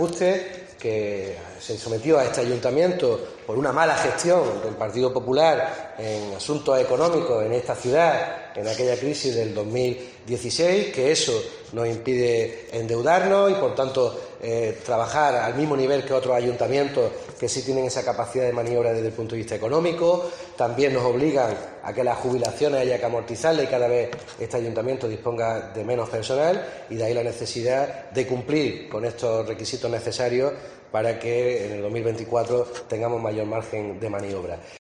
Jacobo Calvo, portavoz del equipo de Gobierno